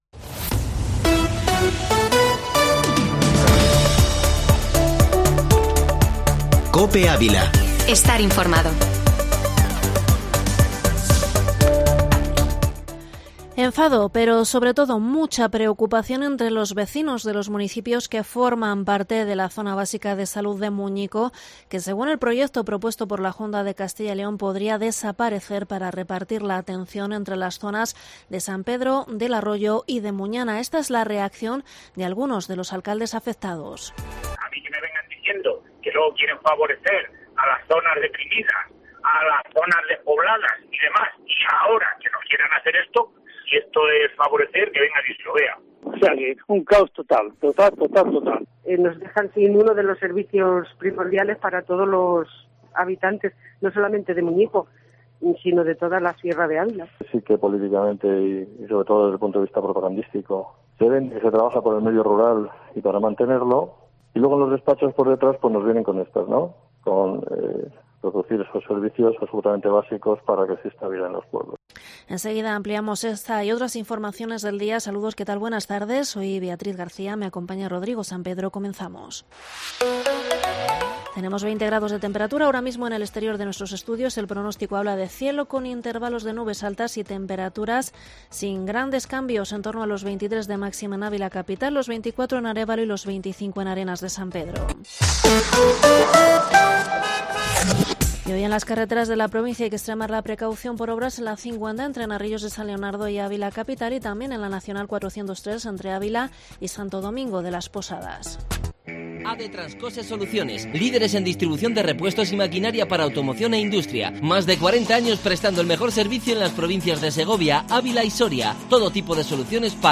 informativo Mediodía COPE ÁVILA 20/10/2021